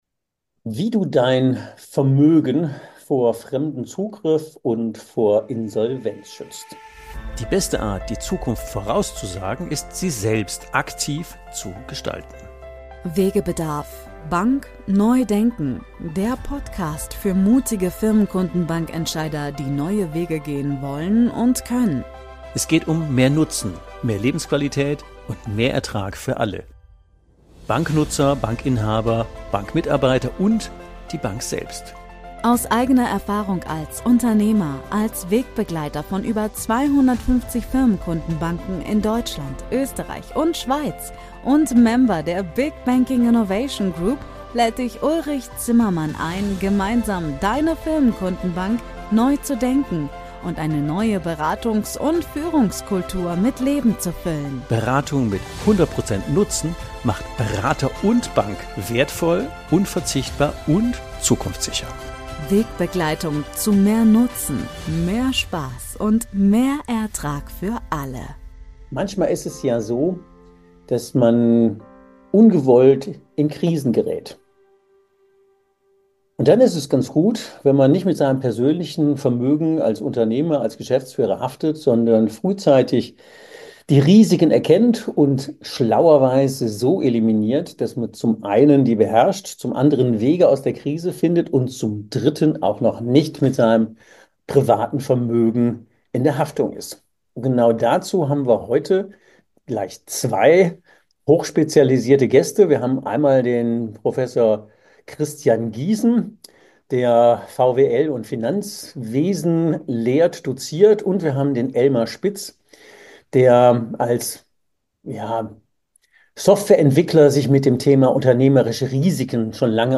In dieser Folge sprechen wir offen und praxisnah